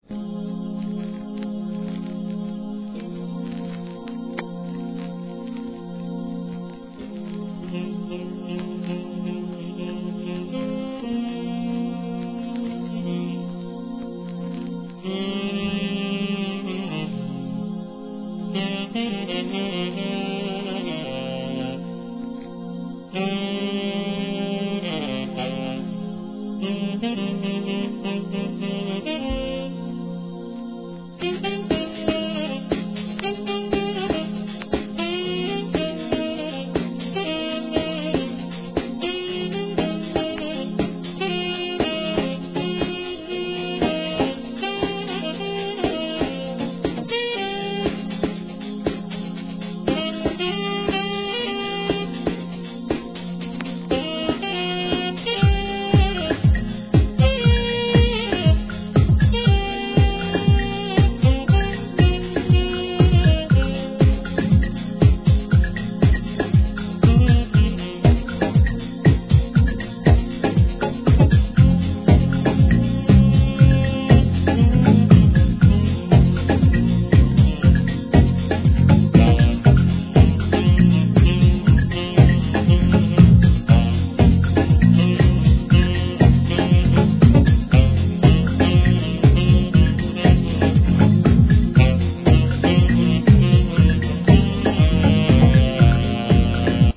HIP HOP/R&B
暖かくソウルフルな男女ヴォーカルを響かせた逸品!